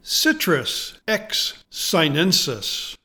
Pronounciation:
CI-trus X sigh-NEN-sis